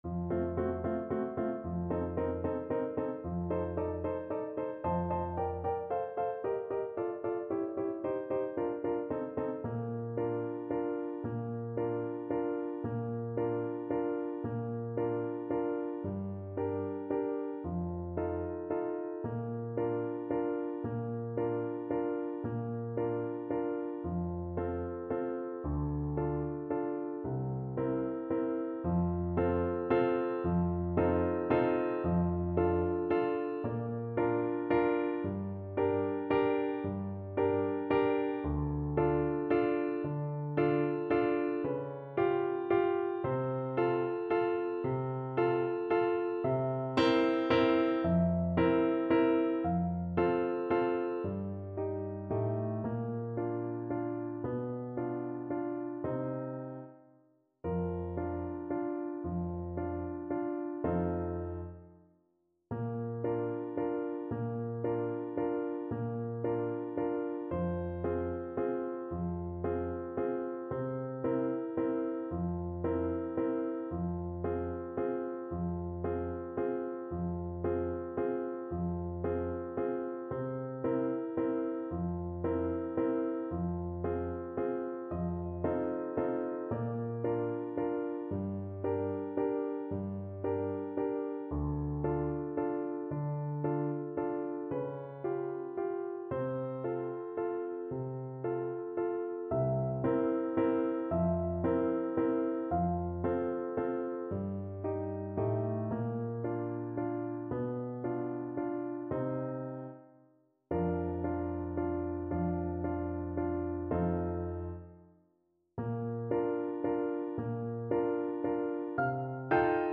Adagio =50
Classical (View more Classical Clarinet Music)